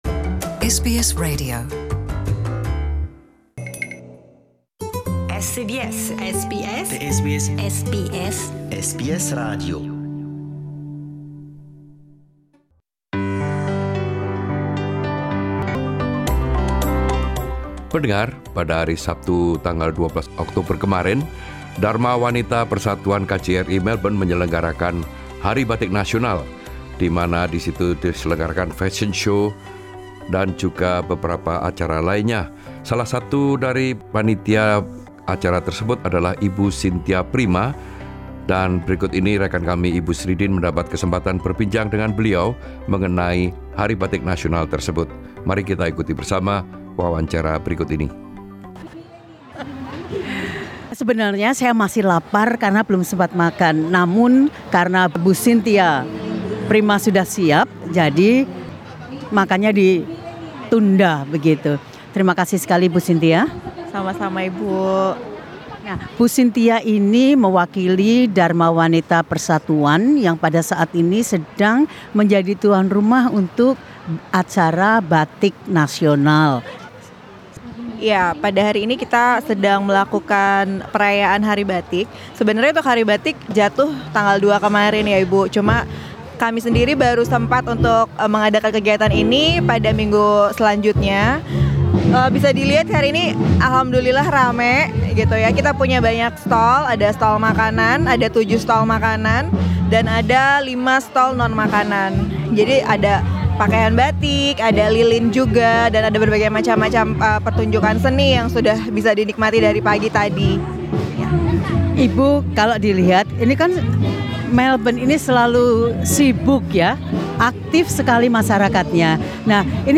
talking to SBS Indonesian at the Batik Bazaar held by Indonesian Women’s Auxiliary at the Indonesian Consulate in Melbourne